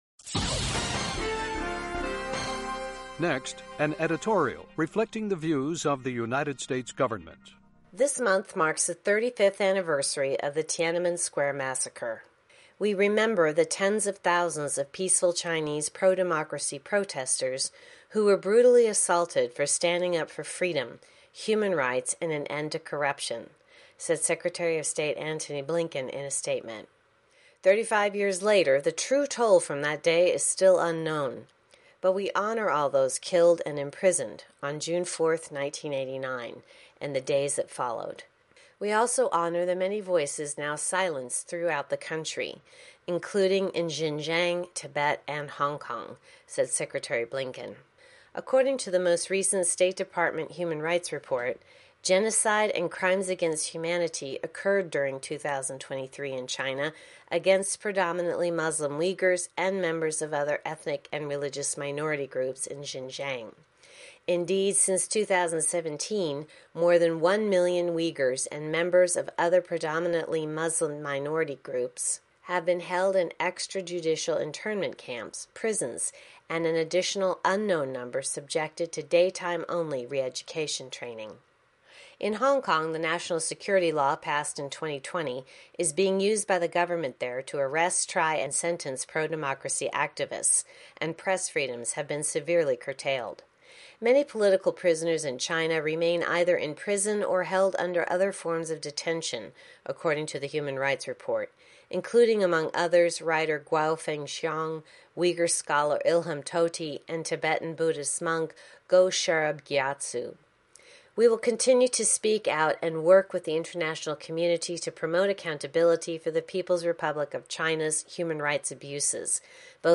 Uvodnik koji odražava stav Vlade SAD